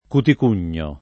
vai all'elenco alfabetico delle voci ingrandisci il carattere 100% rimpicciolisci il carattere stampa invia tramite posta elettronica codividi su Facebook cuticugno [ kutik 2 n’n’o ] s. m. — antico nome di un rozzo soprabito